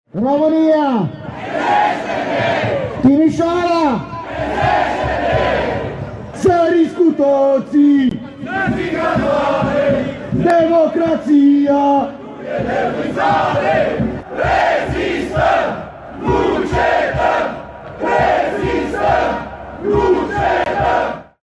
25feb-20-ambianta-buna-protest-Timisoara-.mp3